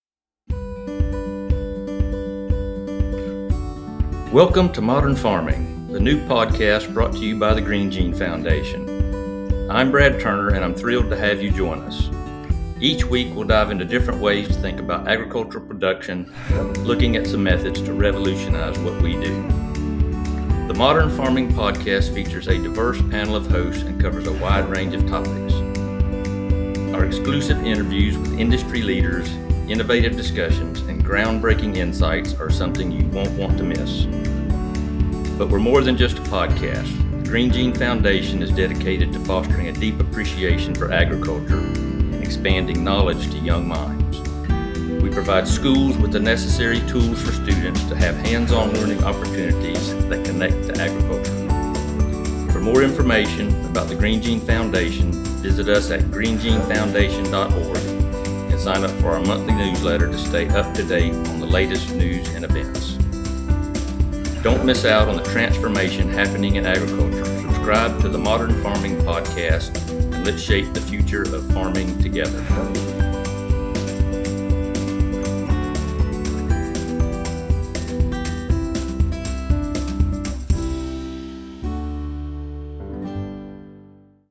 The Modern Farming Podcast features a diverse panel of hosts and covers a wide range of topics.